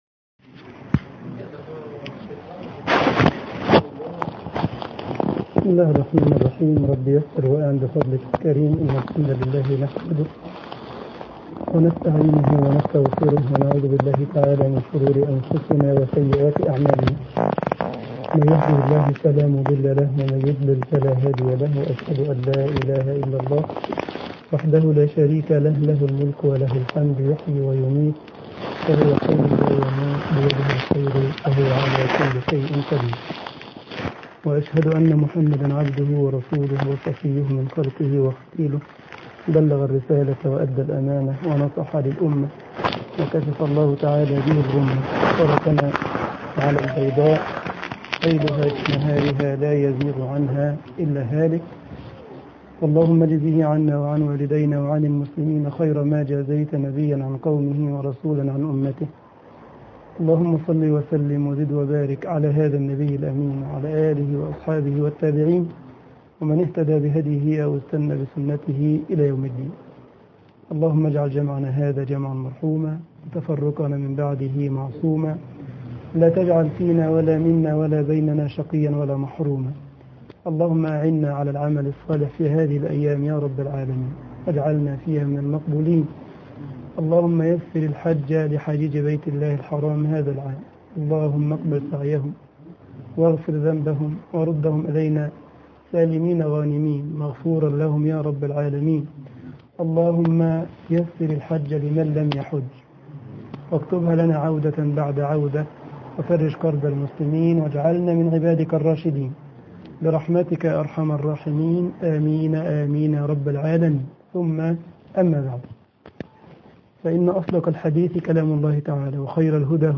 محاضرة
جمعية الشباب المسلمين بالسارلاند - ألمانيا